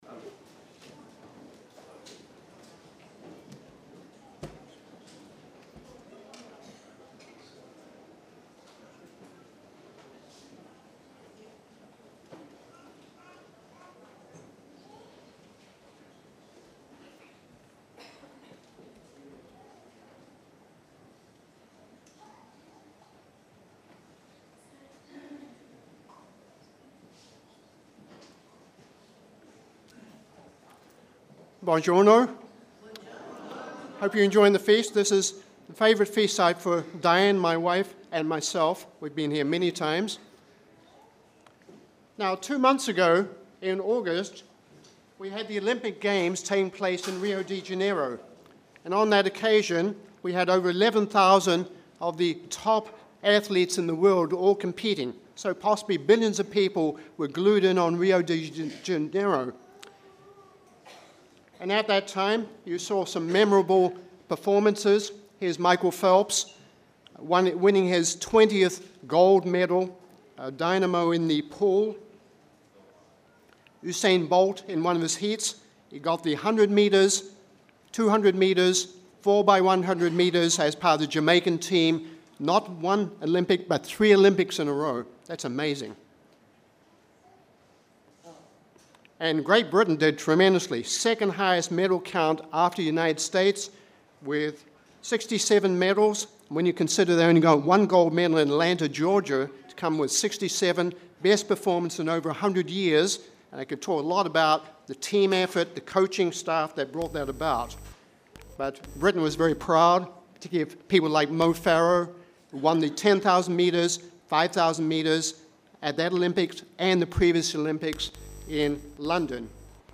English Message